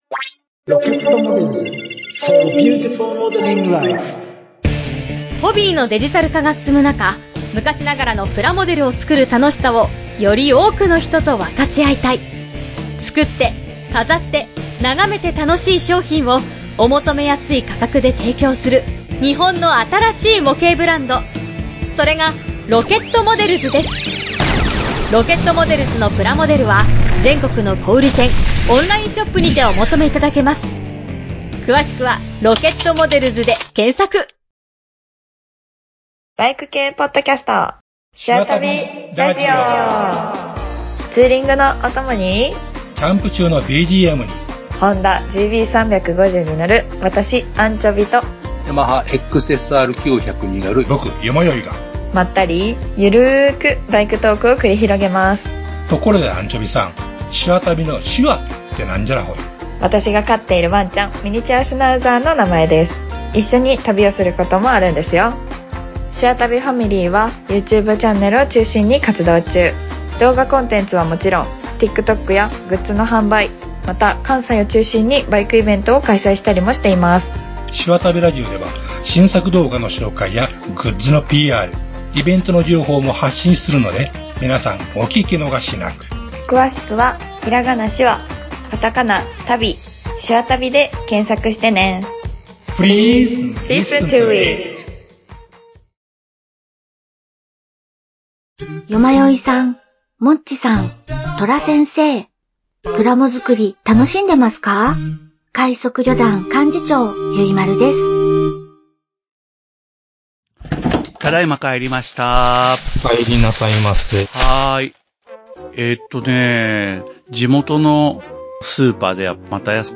趣味が高じて模型屋「エニグマ工房」を営む事になった店主が、バイト店員や常連客たちとプラモデルの情報交換やアニメ・漫画・ミリタリーなどオタクな話題を繰り広げる…という設定のポッドキャスト番組を配信するブログです！